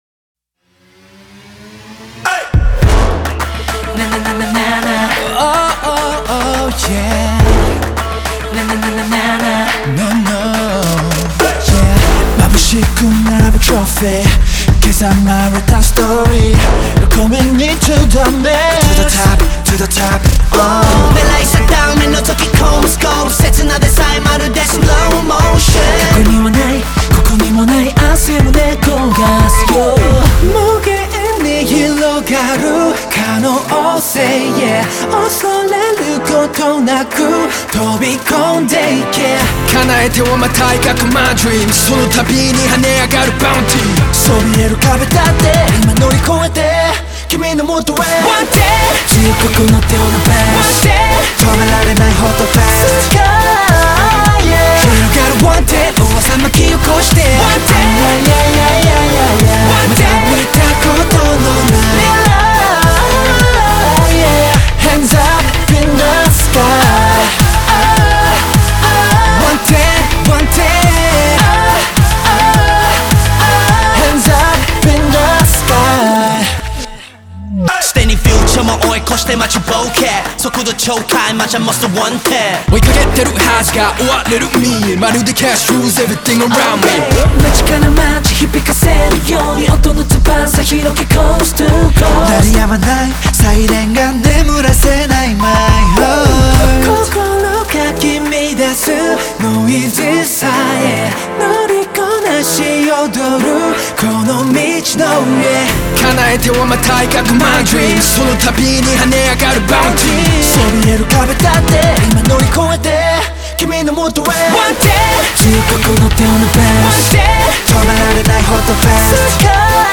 мощными битами и запоминающимися мелодиями